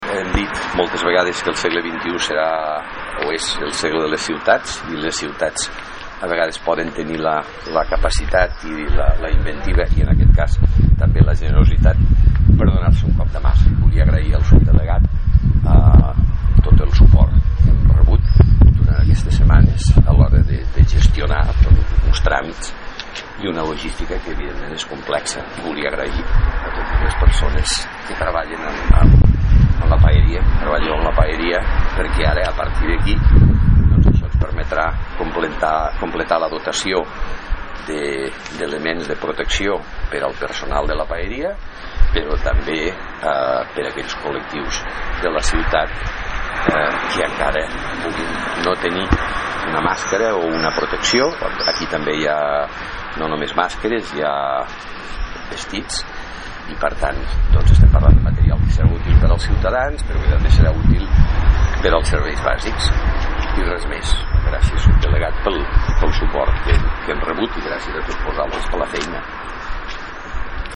tall-de-veu-miquel-pueyo